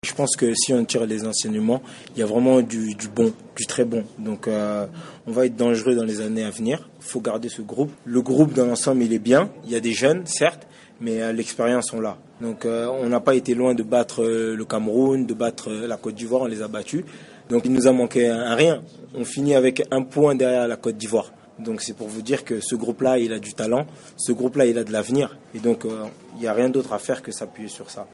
Youssouf Mulumbu, joueur congolais, au micro de Top Congo notre partenaire à Kinshasa